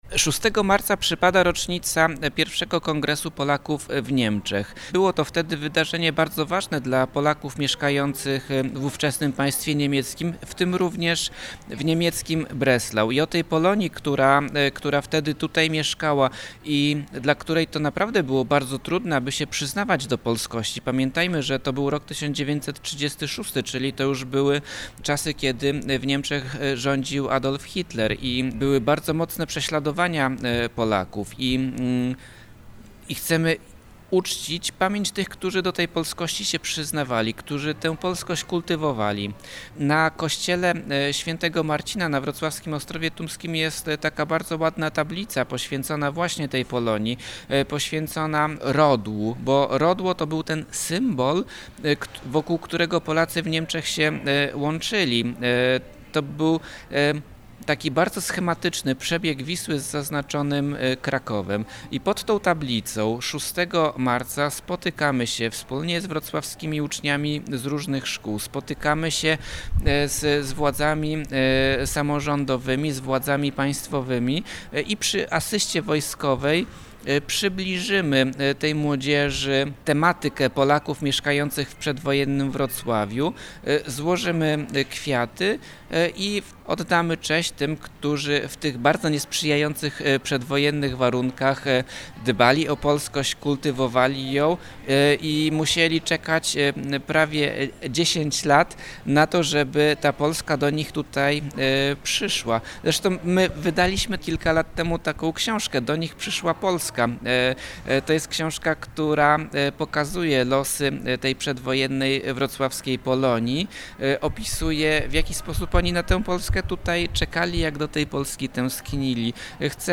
DWK_Polacy-spod-znaku-rodla-rozmowa-i-zaproszenie-na-uroczystosci-2025-DD.mp3